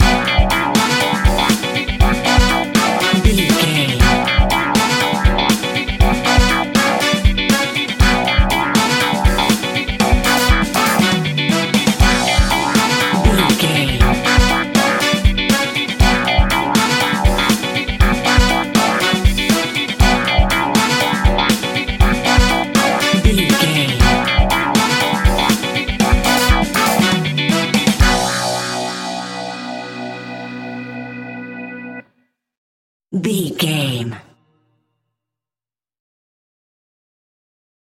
Aeolian/Minor
groovy
uplifting
bouncy
drums
electric guitar
bass guitar
horns
funky house
disco house
electronic funk
upbeat
synth leads
Synth pads
synth bass
drum machines